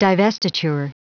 Prononciation du mot divestiture en anglais (fichier audio)
Prononciation du mot : divestiture